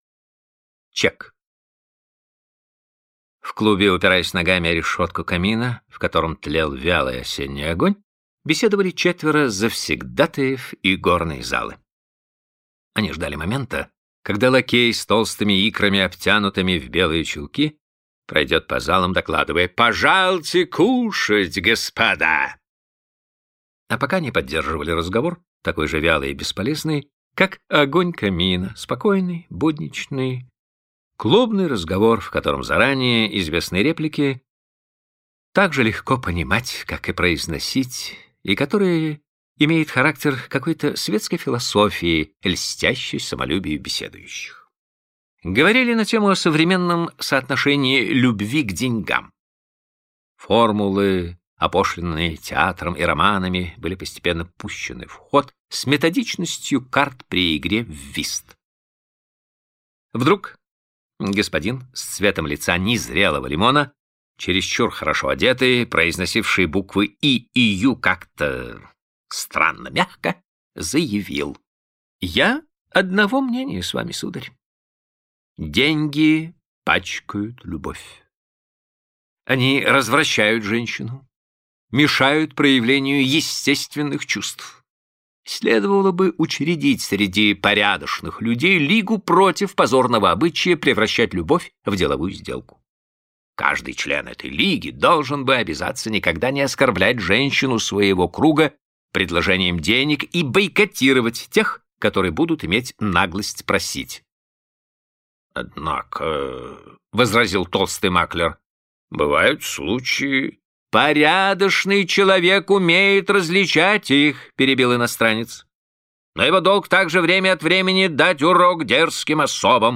Аудиокнига Статуя и другие рассказы | Библиотека аудиокниг